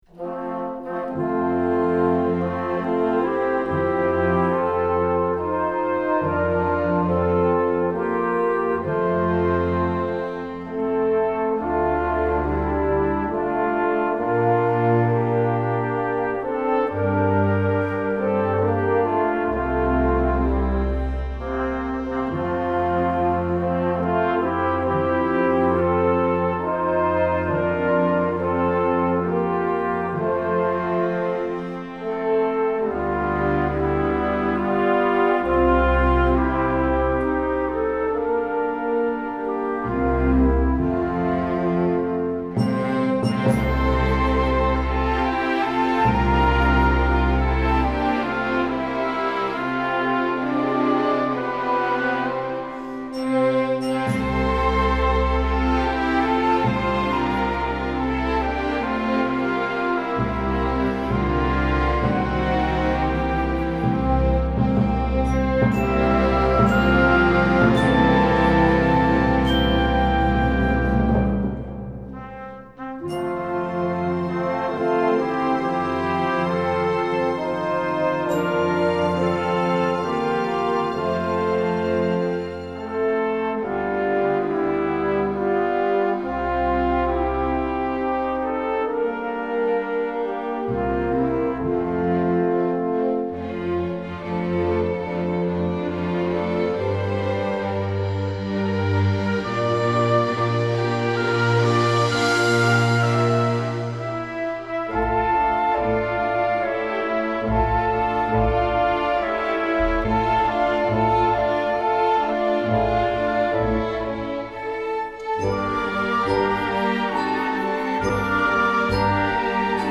Instrumentation: full orchestra
classical, children